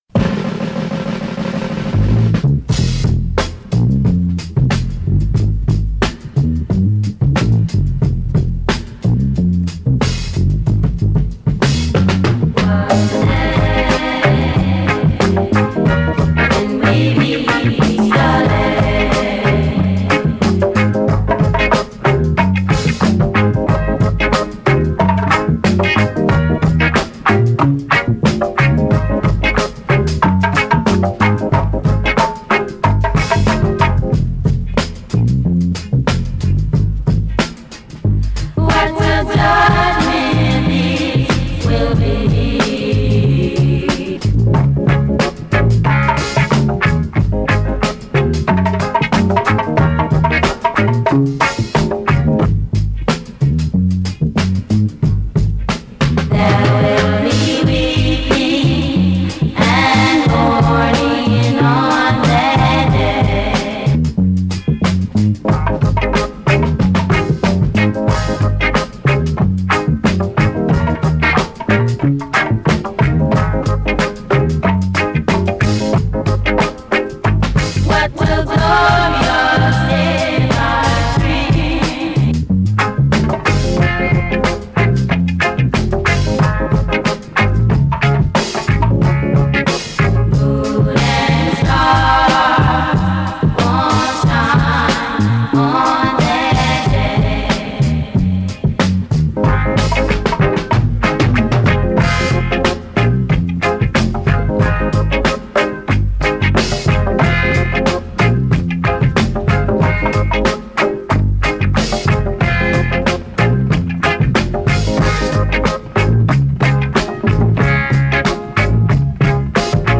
Attention: gros dub